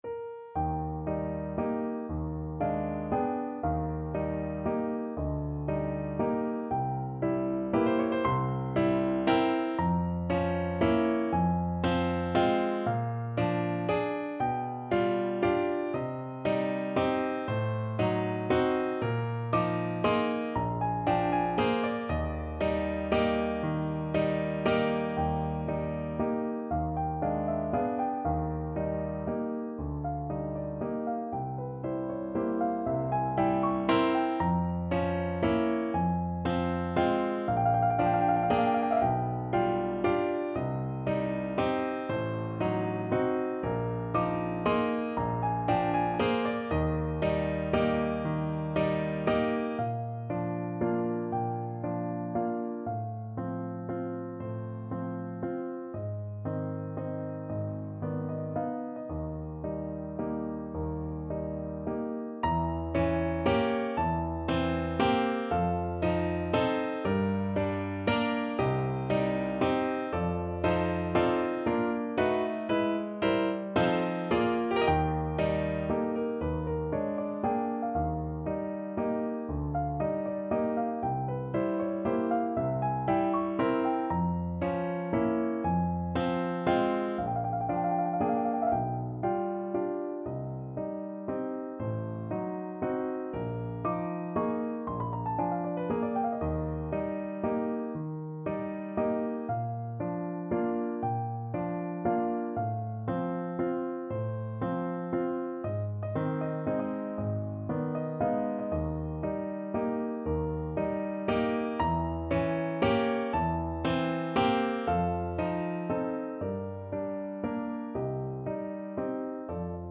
No parts available for this pieces as it is for solo piano.
Andante .=39
12/8 (View more 12/8 Music)
Piano  (View more Advanced Piano Music)
Classical (View more Classical Piano Music)
the tempo is quite fast here.